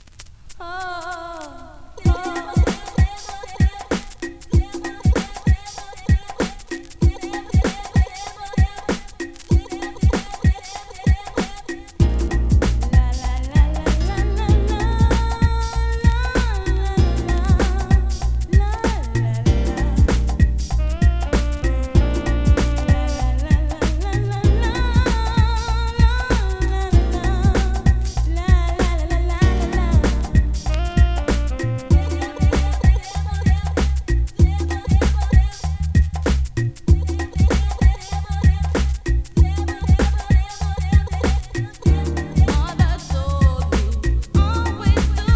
2026 NEW IN!! DANCEHALL!!
スリキズ、ノイズ比較的少なめで